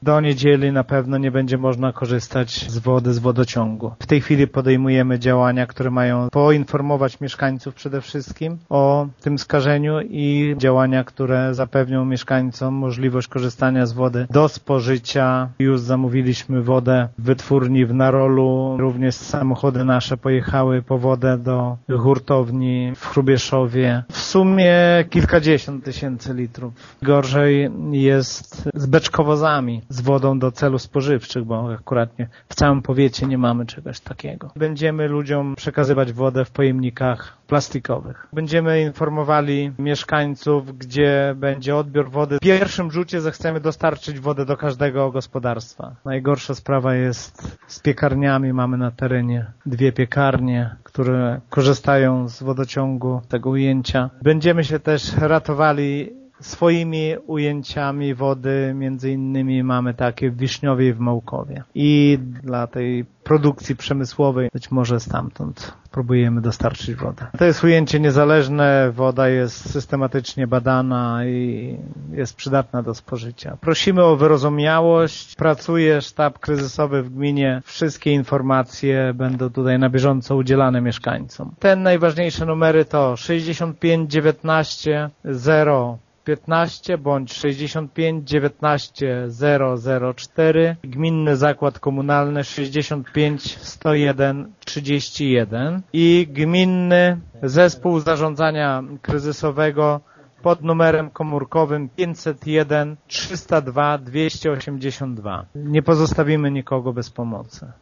„Na razie woda jest chlorowana. Jutro Sanepid ma pobrać kolejne próbki do badań, których wyniki poznamy prawdopodobnie w niedzielę” – informuje wójt Gminy Mircze Lech Szopiński: